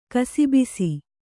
♪ kasibisi